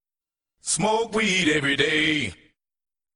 ghbot - Discord version of greenhambot -- currently just plays meme sfx in voice channels + static text commands
heyheyhey.mp3